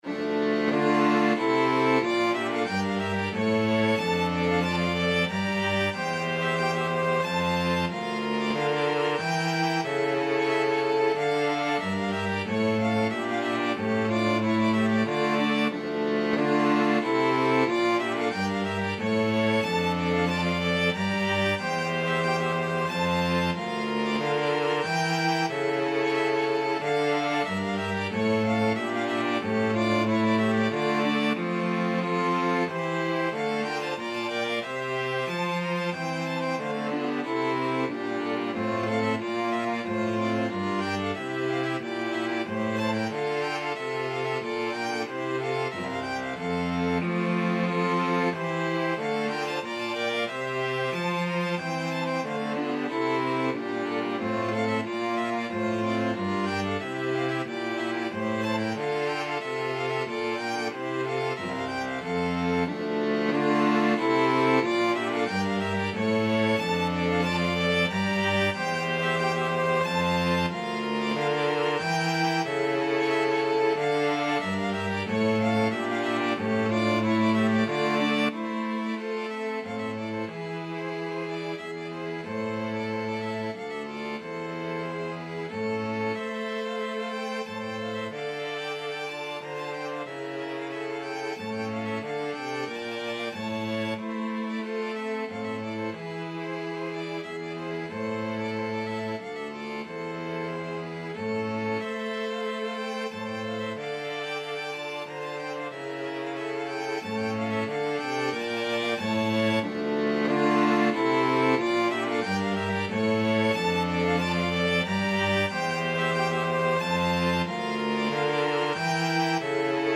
Violin 1Violin 2ViolaCello
Allegro Moderato = c. 92 (View more music marked Allegro)
3/2 (View more 3/2 Music)
Classical (View more Classical String Quartet Music)